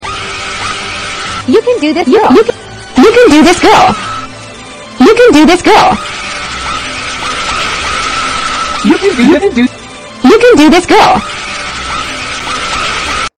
Transcription You Can Do This Girl You Can Do This Girl with screaming in the background